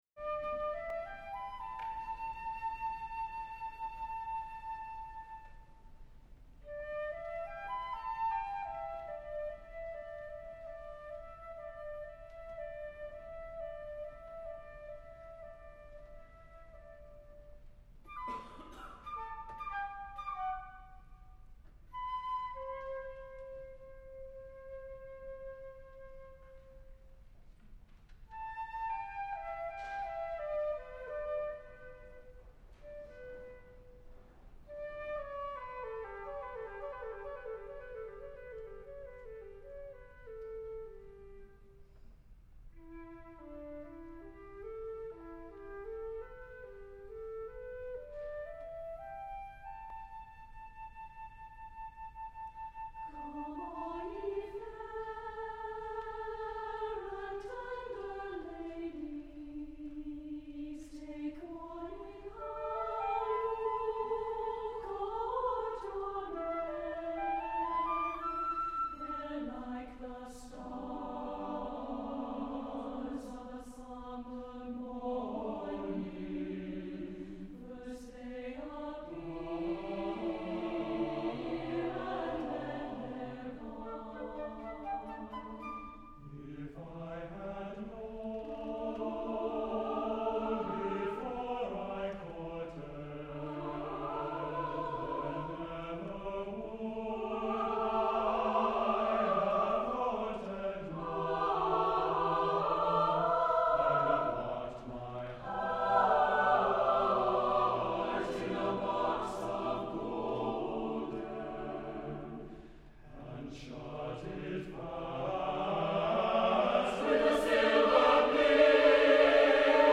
for SATB Chorus and Flute (1992)